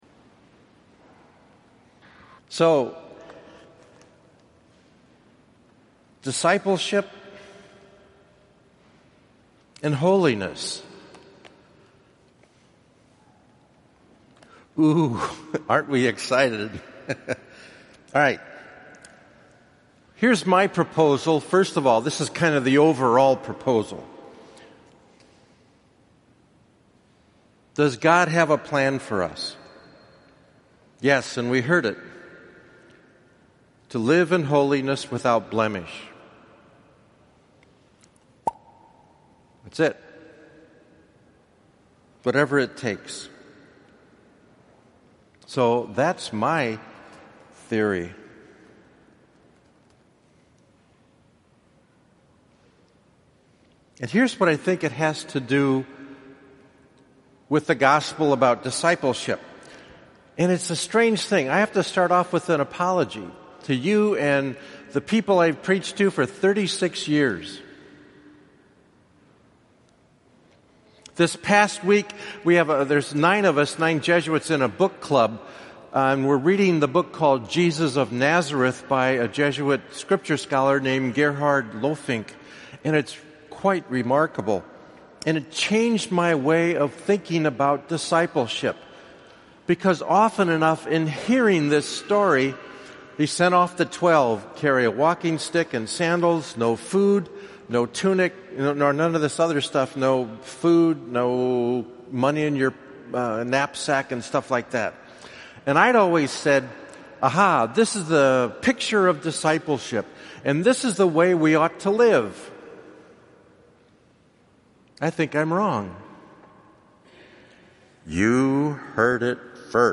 Fifteenth Sunday of Ordinary Time (6:00 pm Sunday)
Homily